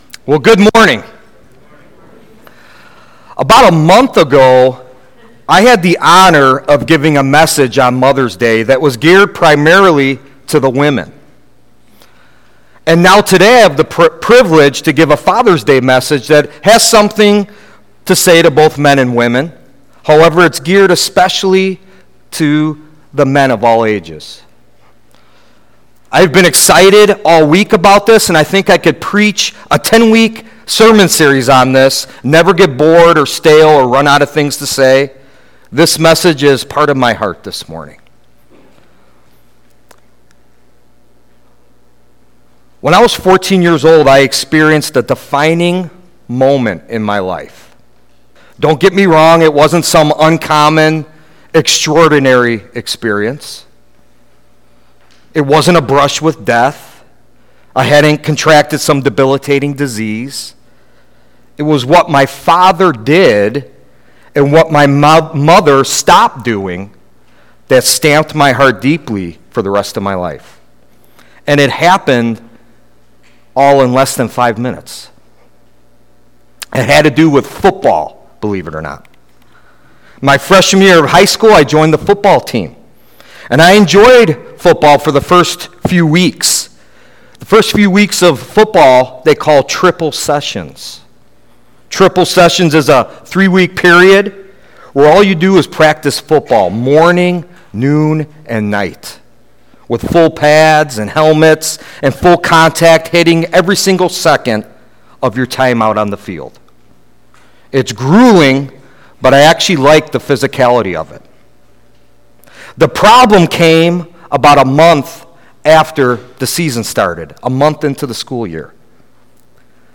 We concluded our “Family Time” series with a special sermon on Father’s Day entitled the “The Courage To Step Up.”